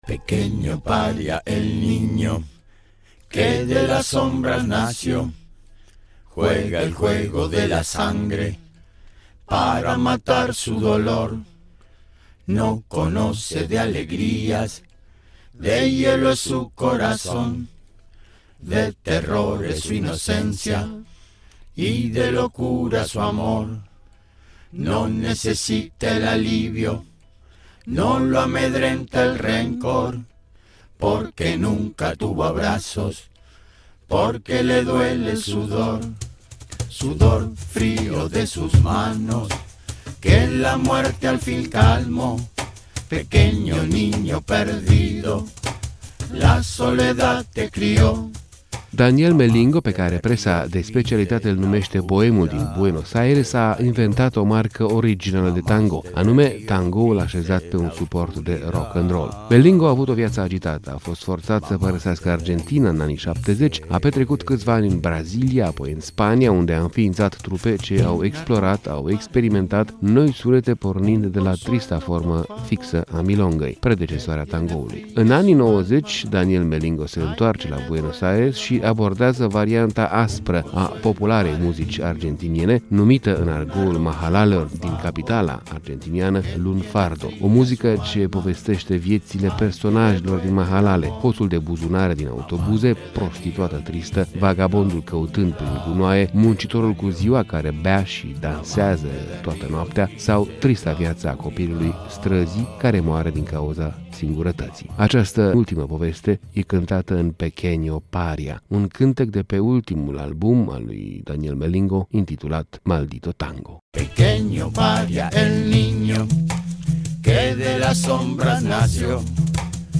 O varianta varianta aspră a tango-ului argentinian